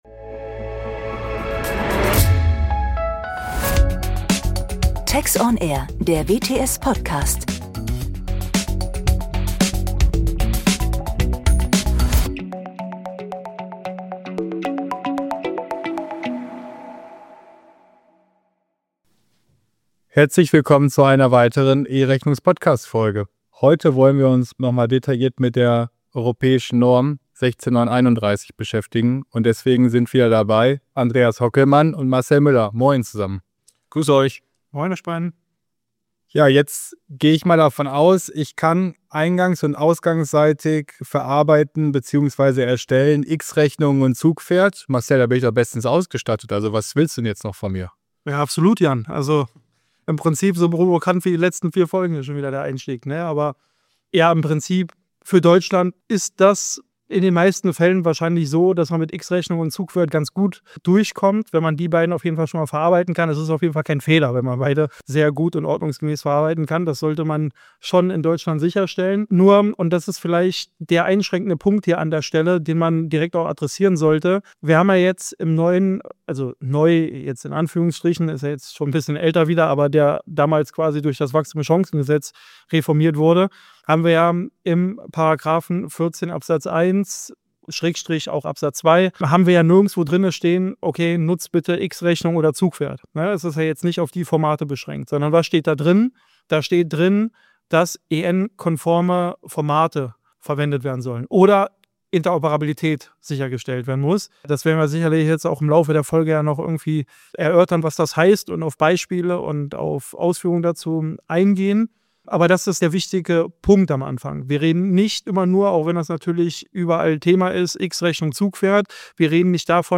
Die Podcast-Reihe „Einfach E-Rechnung“ beleuchtet im Rahmen von kurzweiligen Expertengesprächen neben den aktuellen rechtlichen Entwicklungen und Rahmenbedingungen in Deutschland auch viele technische und prozessuale Fragestellungen im Rechnungseingangs- und Rechnungsausgangsprozess.